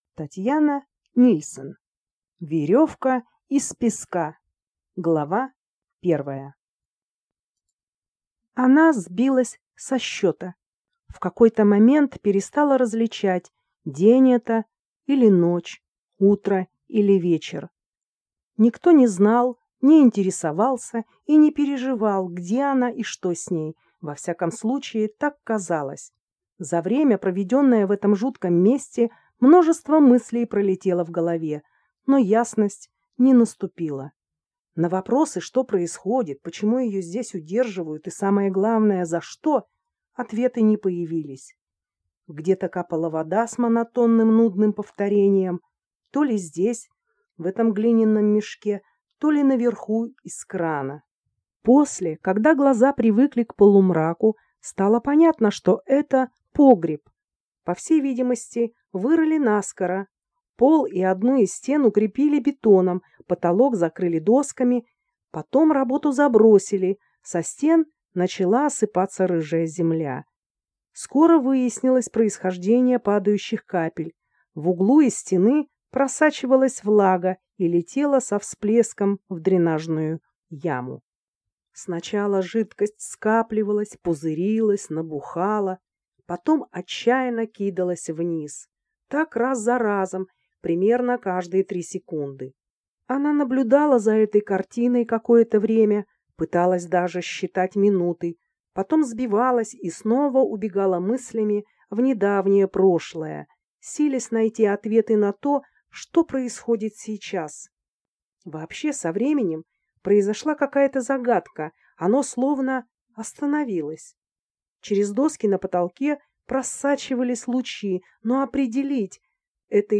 Аудиокнига Верёвка из песка | Библиотека аудиокниг